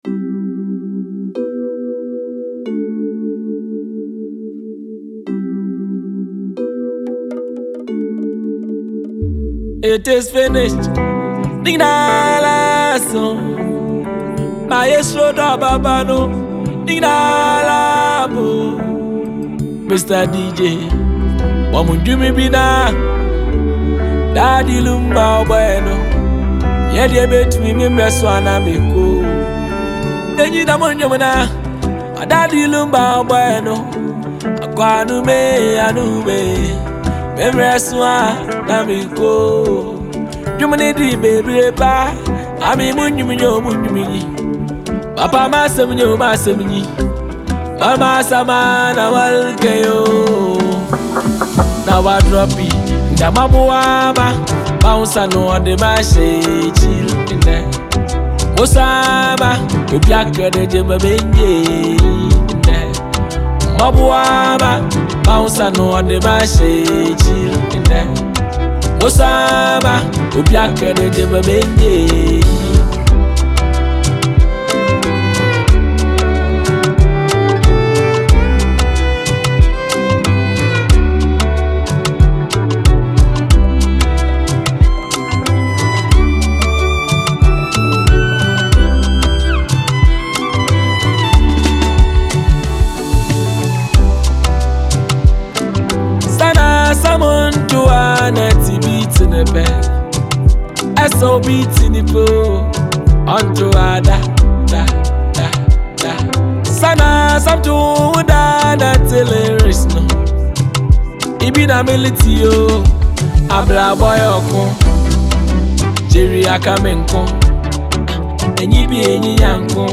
Afrobeats and Highlife